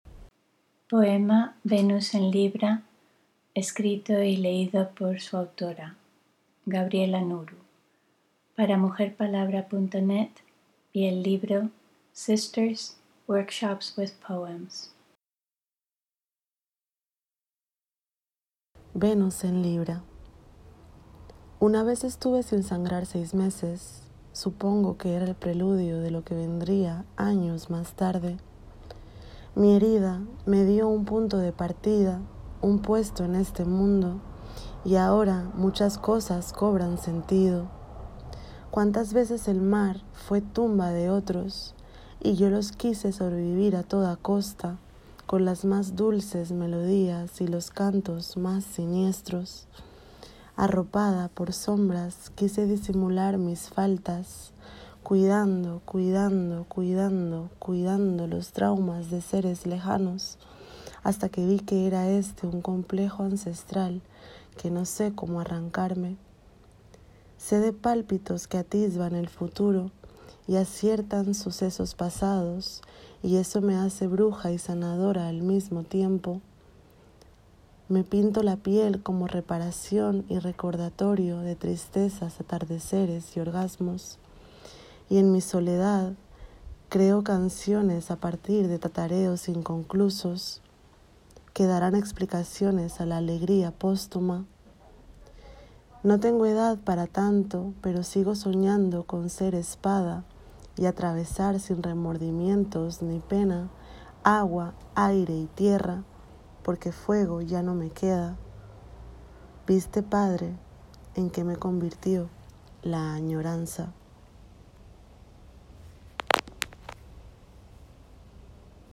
Escuchar el poema en la voz de su autora